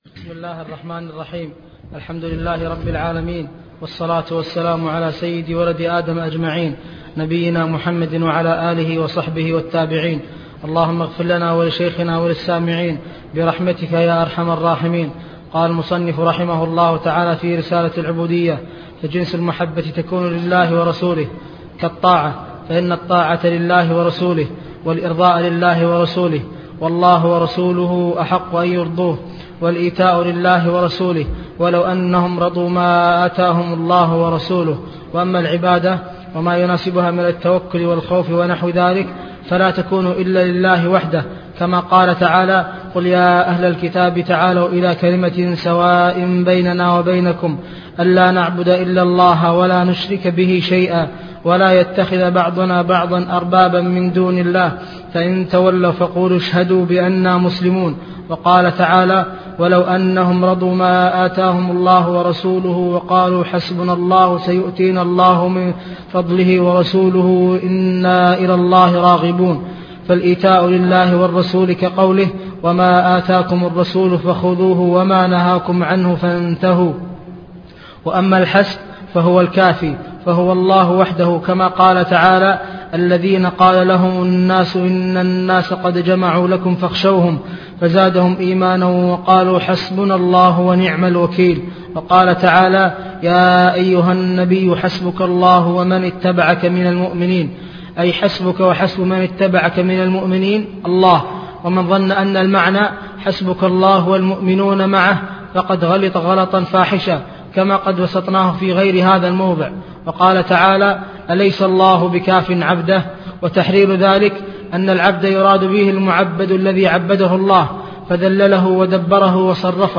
تفاصيل المادة عنوان المادة الدرس (2) شرح رسالة العبودية تاريخ التحميل الخميس 9 فبراير 2023 مـ حجم المادة 33.01 ميجا بايت عدد الزيارات 150 زيارة عدد مرات الحفظ 63 مرة إستماع المادة حفظ المادة اضف تعليقك أرسل لصديق